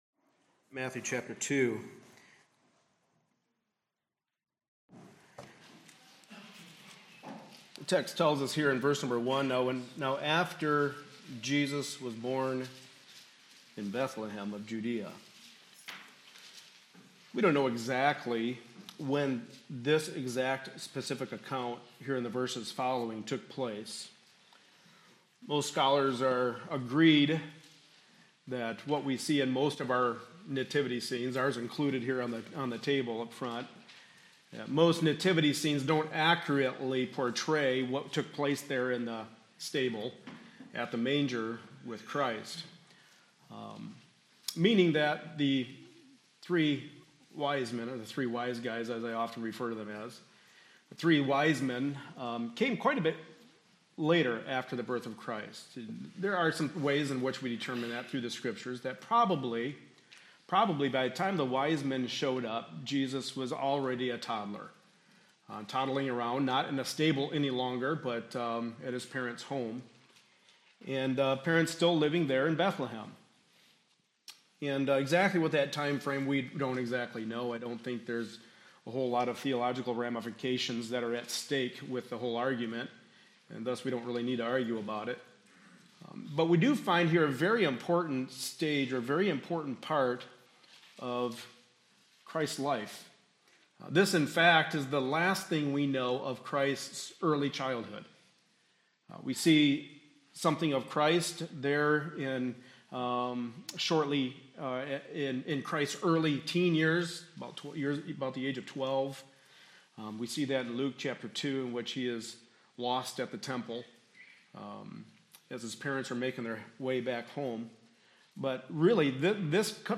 Passage: Matthew 2:1-12; 1 Timothy 6:13-16 Service Type: Sunday Morning Service Related Topics